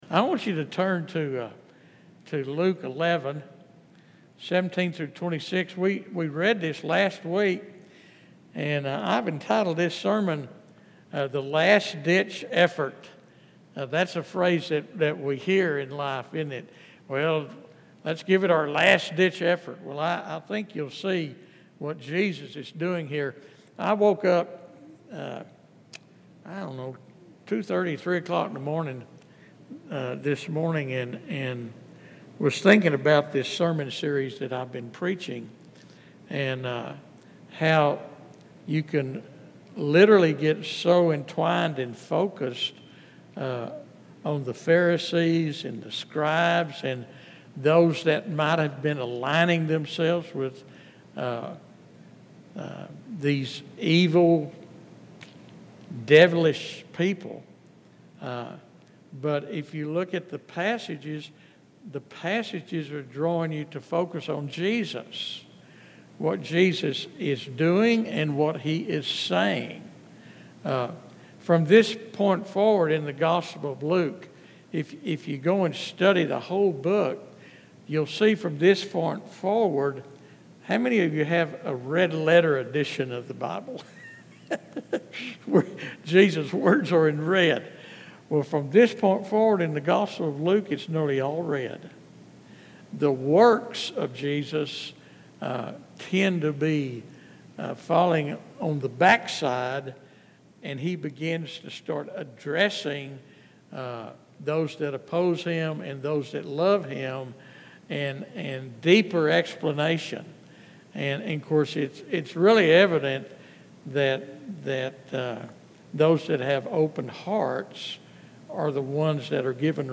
This sermon challenges both religious people who rely on outward appearances and anyone seeking genuine transformation.